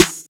Snares
BREZ_SNR.wav